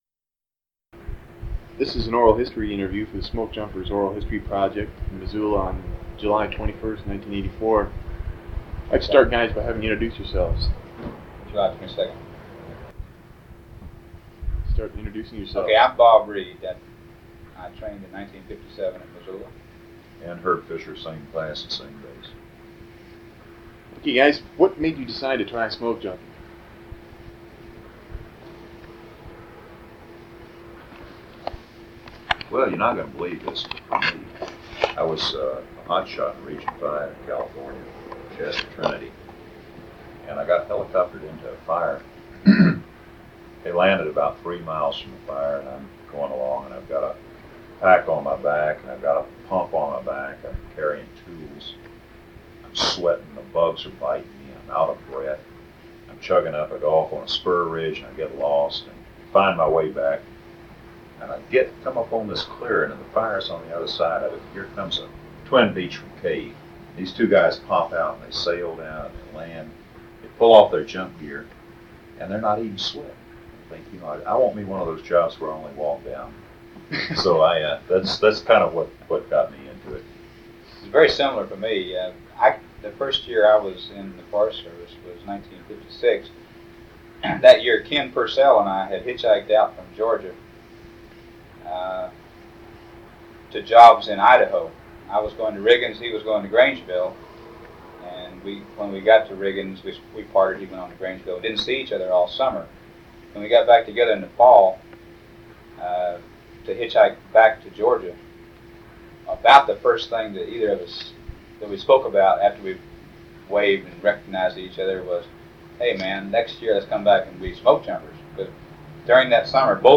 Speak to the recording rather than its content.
1 sound cassette (66 min.) : analog